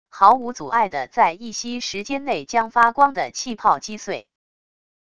毫无阻碍的在一息时间内将发光的气泡击碎wav音频